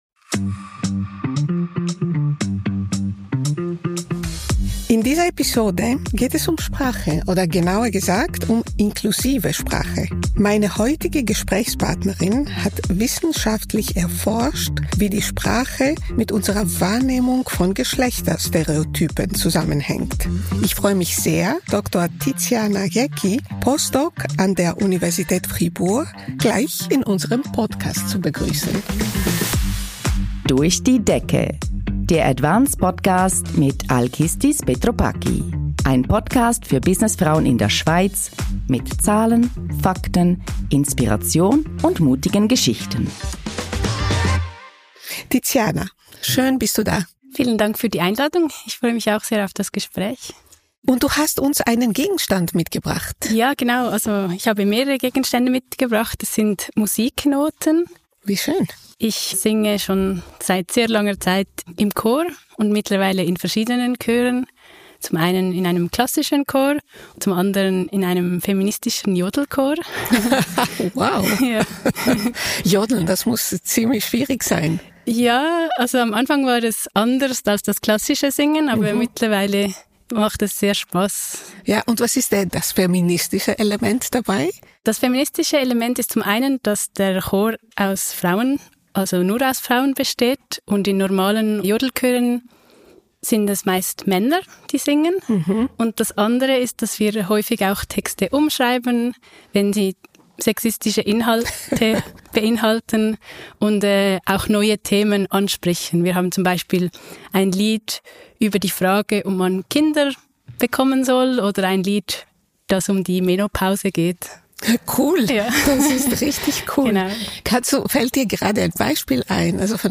Ein Gespräch über Fakten aus der aktuellen Forschung der Psycholinguistik mit Tipps zum Gendern, die sich ganz leicht in den Alltag einbauen lassen – auch in einem Jodelchor.